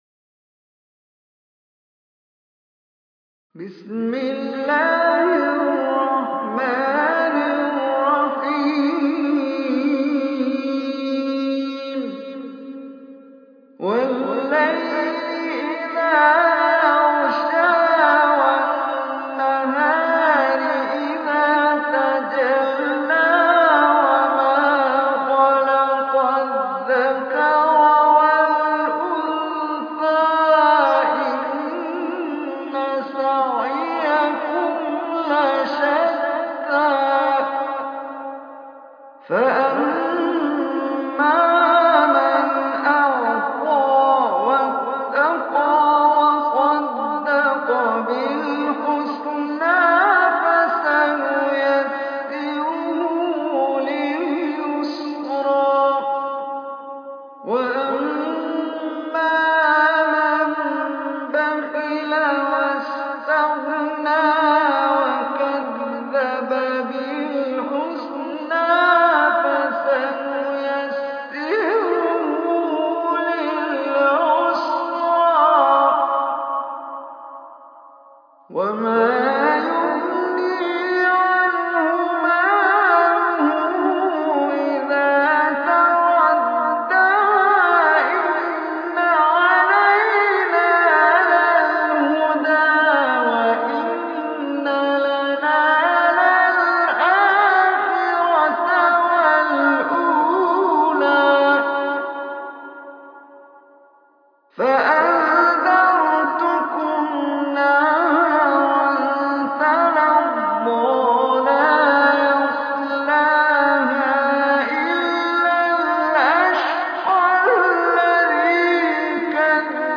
Surah Lail Recitation by Omar Hisham Arabi
Surah Lail is 92 surah of Holy Quran. Listen or play online mp3 recitation in arabic in the beautiful voice of Sheikh Omar Hisham Al Arabi.